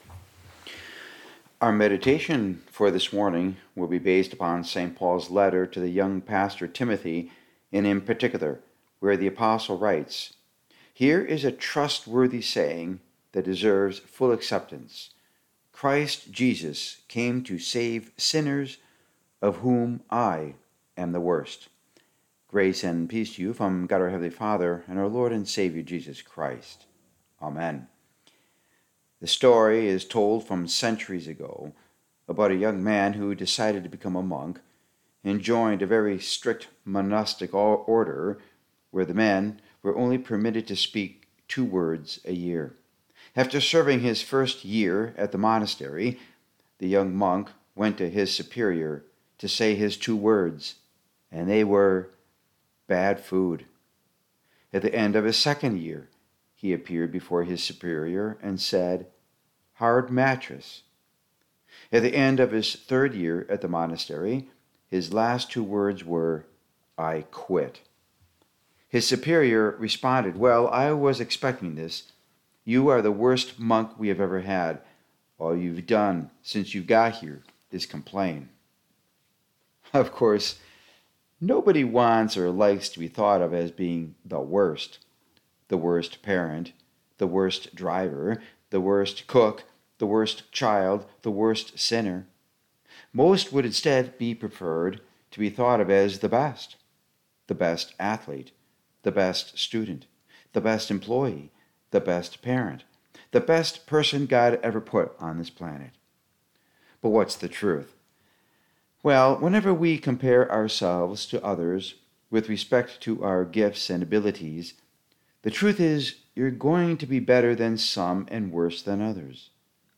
7-6-2025 Sermon